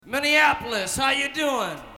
Tags: Jim Morrison quotes The Doors Jim Morrison Poems Jim Morrison spoken word Poetry CD